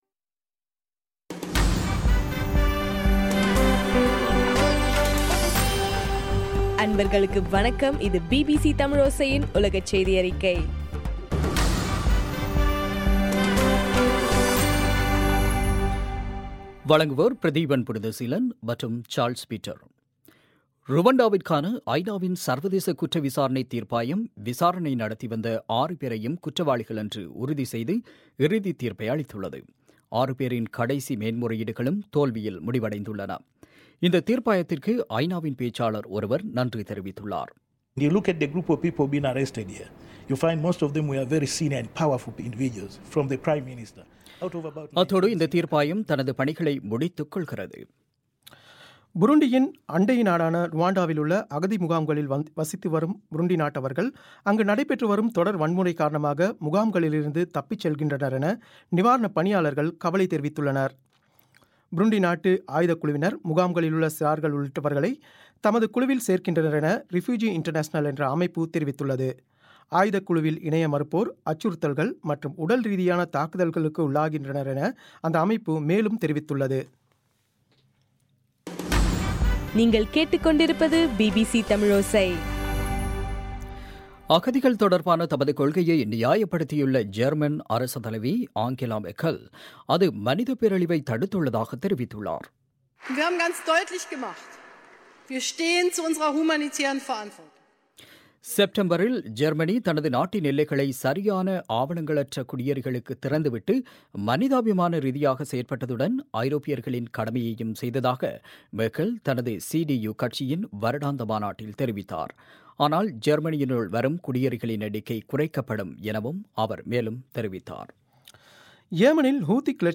இன்றைய (டிசம்பர் 14) பிபிசி தமிழோசை செய்தியறிக்கை